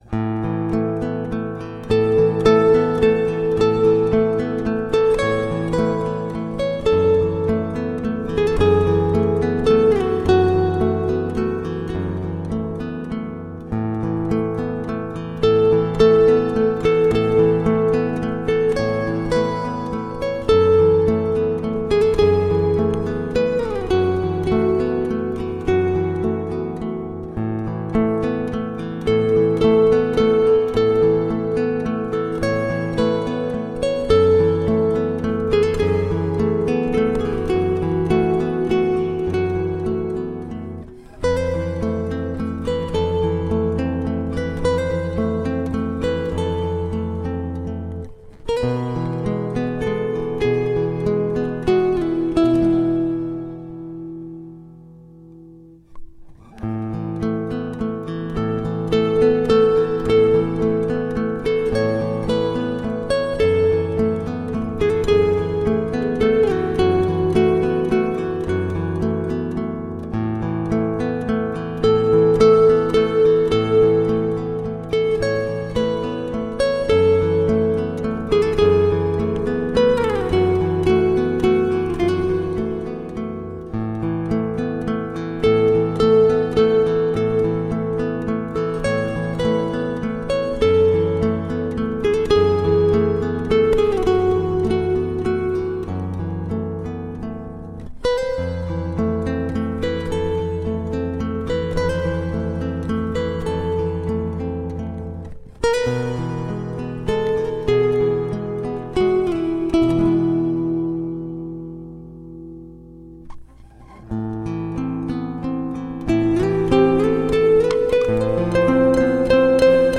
Layers of lush acoustic guitar.